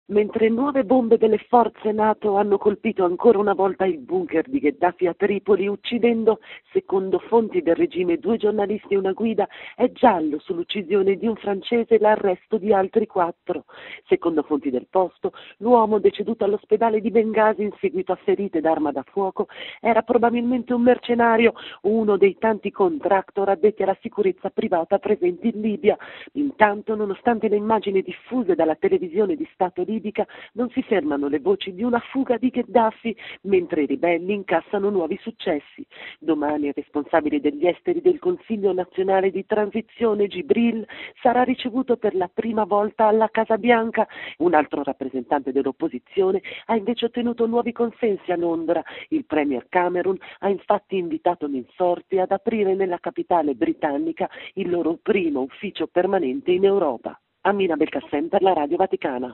Sul terreno, intanto, i ribelli - dopo aver conquistato l'aeroporto di Misurata - marciano ora su Zliten, a circa 60 km. Il servizio